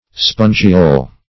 Search Result for " spongiole" : The Collaborative International Dictionary of English v.0.48: Spongiole \Spon"gi*ole\ (sp[u^]n"j[i^]*[=o]l; 277), n. [L. spongiola a rose gall, small roots, dim. of spongia: cf. F. spongiole.]